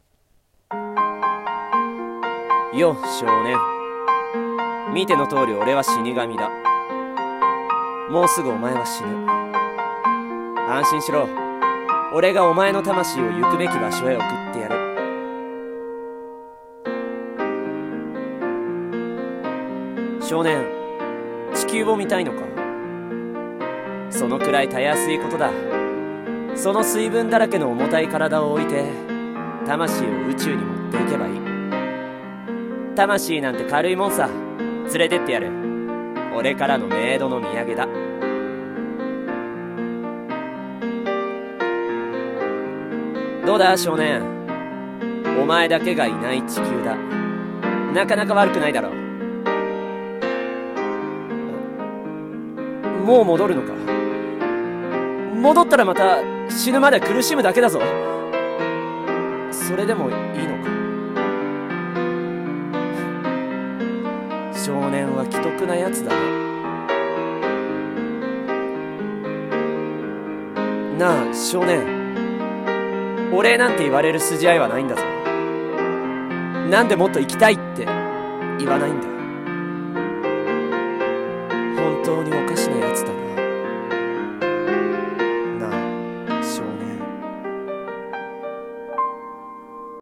声劇「少年と死神」